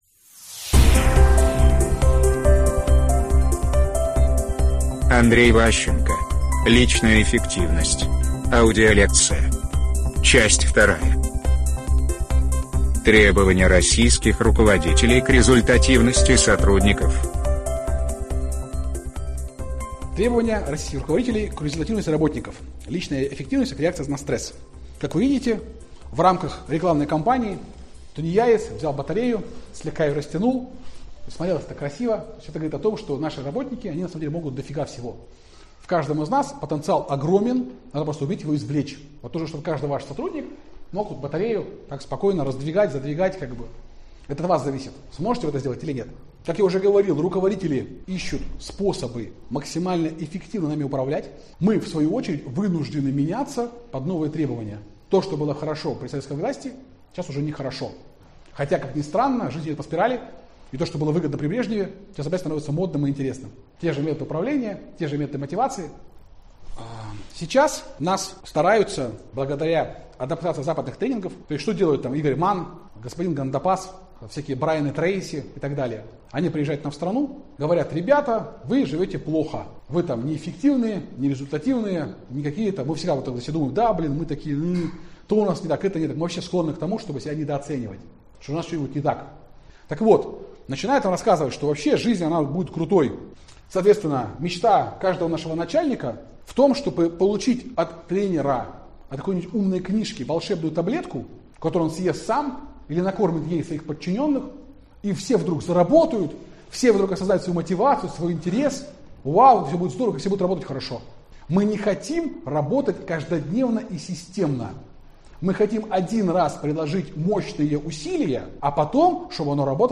Аудиокнига Личная эффективность «по-русски». Лекция 2 | Библиотека аудиокниг